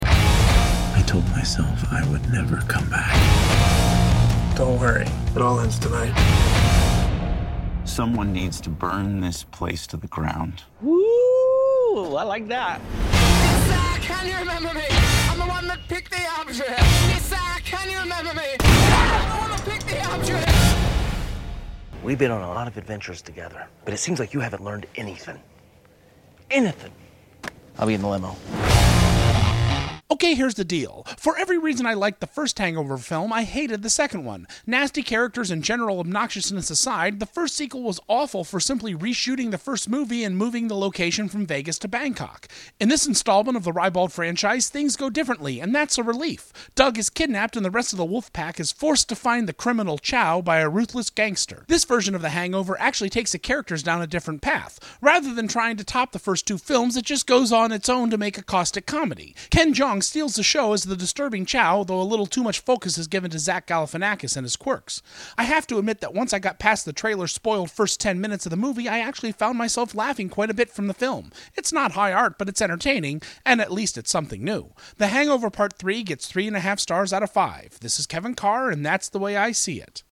“The Hangover: Part III” Movie Review